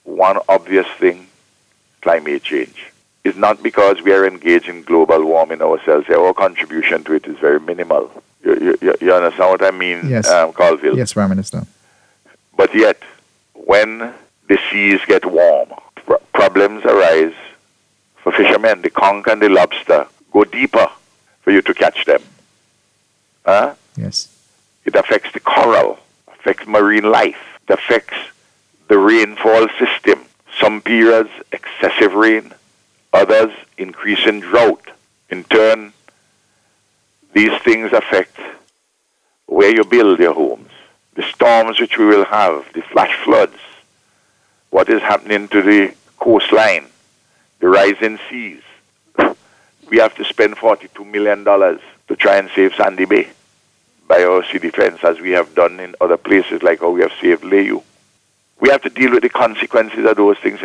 Prime Minister Dr Ralph Gonsalves explained on the Face to Face morning show on NBC Radio yesterday that this is another sea defense project that the government is undertaking to deal with the consequences of climate change.